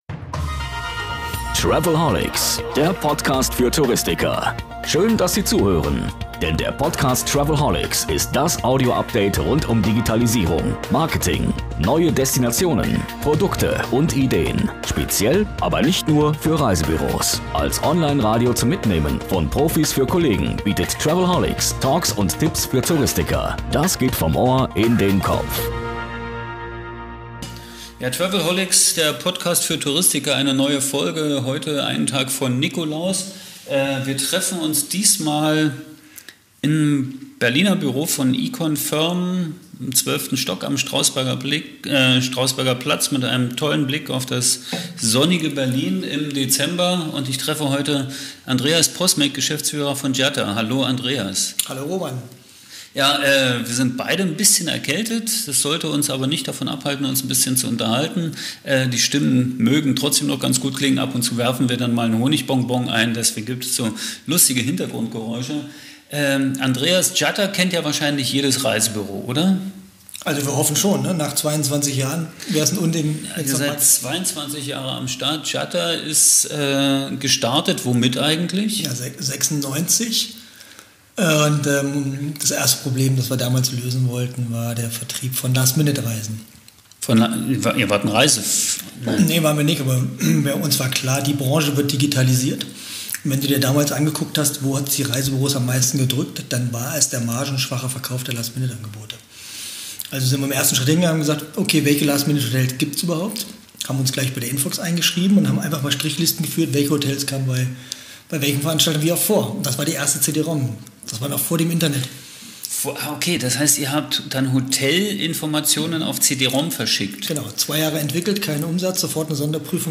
Tech-Talk vom Feinsten, cool erzählt und mit 360 Grad Blick auf Berlin.